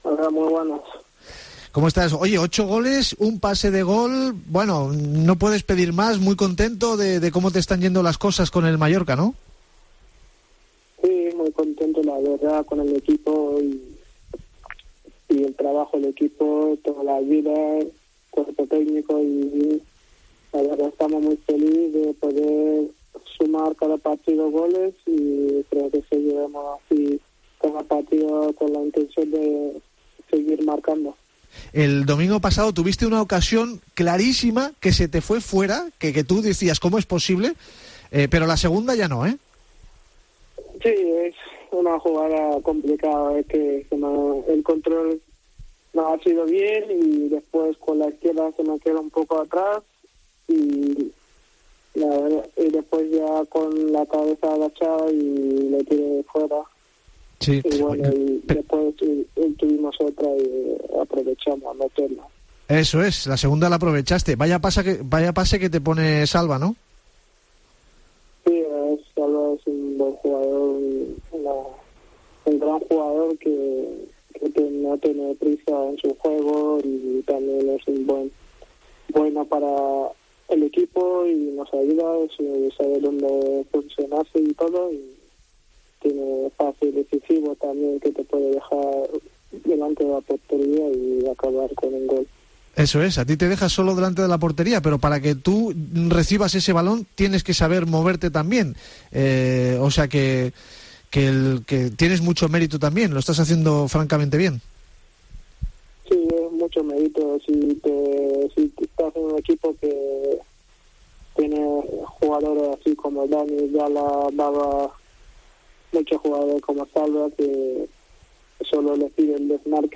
Hablamos con el máximo goleador del RCD Mallorca Amath Ndiaye, con ocho tantos.